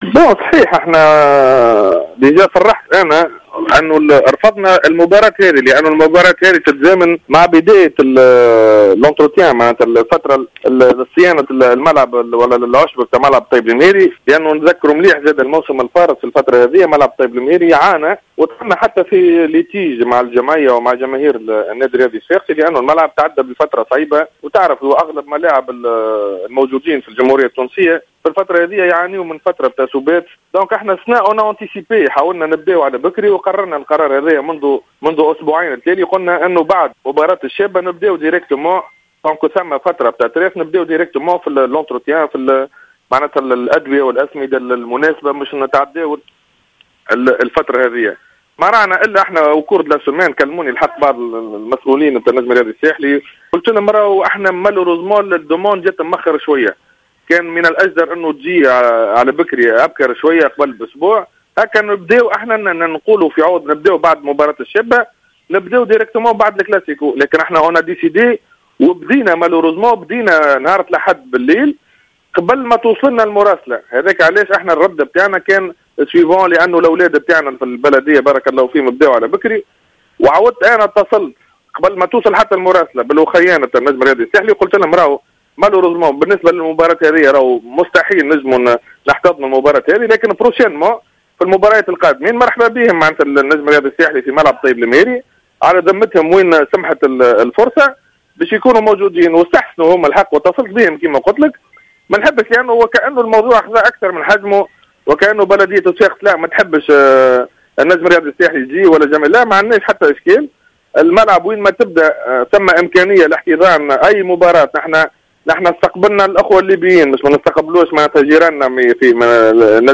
أكد عصام المرداسي رئيس لجنة الشباب و الرياضة في بلدية صفاقس في تصريح خص به جوهرة...